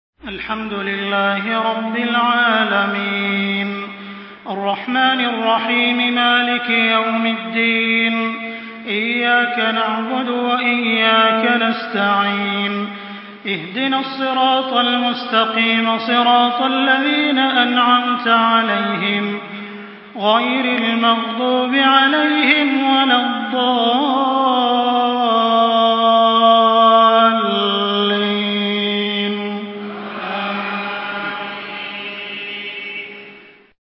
Surah Fatiha MP3 by Makkah Taraweeh 1424 in Hafs An Asim narration.
Murattal